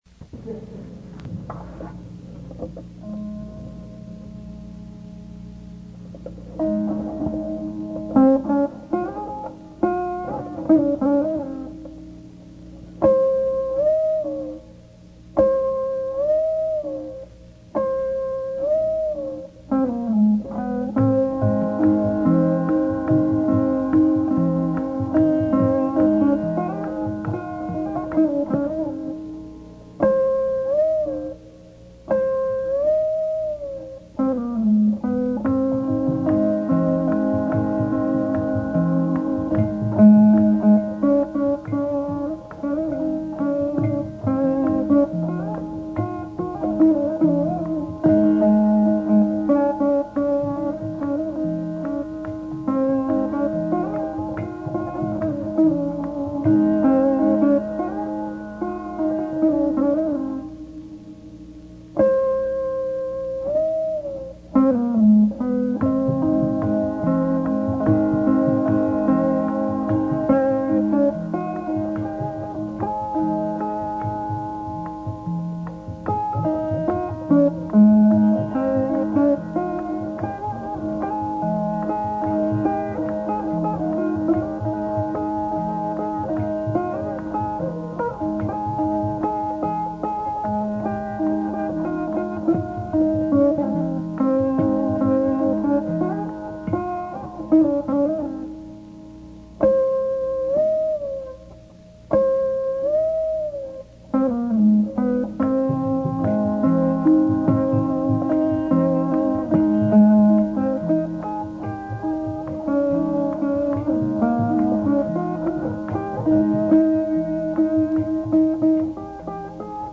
Carnatic Musicians
sounds mAnD..ish to me
It mostly sounded like mAND to me too.